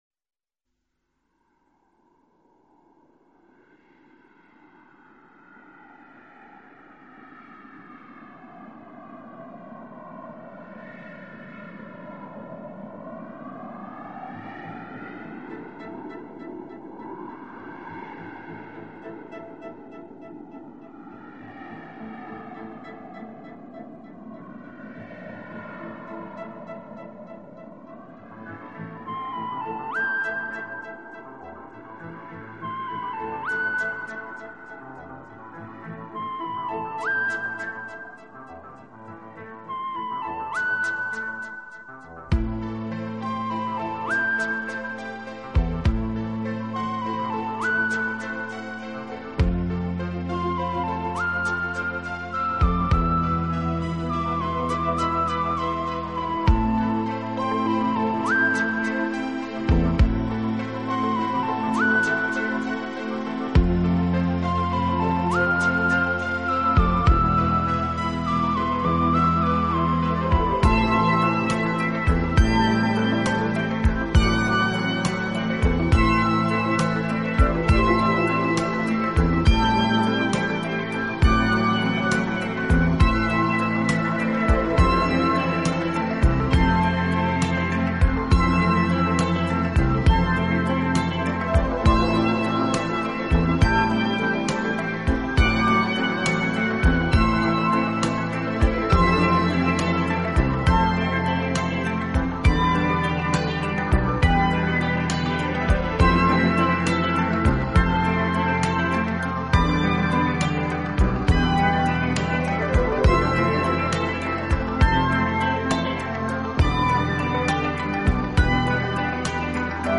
【纯音乐】
的音乐总是给人那幺大气、庄严和堂皇的感觉。